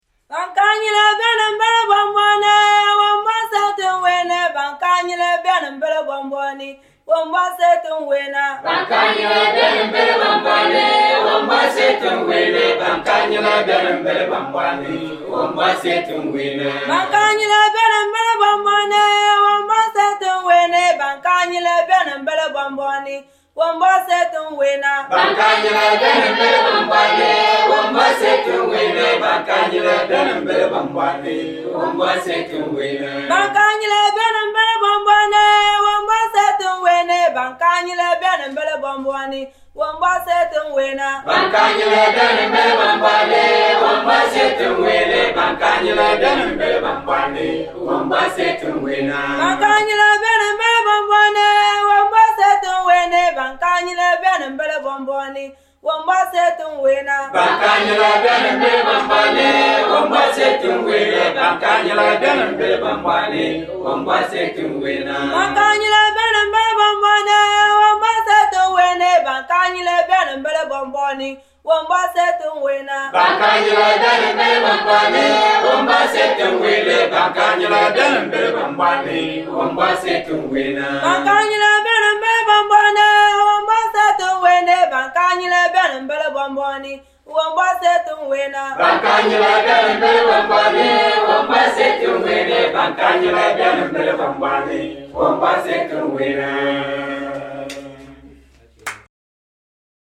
Music
This time, you get a treat… folks from Komba-land singing! See previous posts for the pictures of the choir, made from the churches of several villages.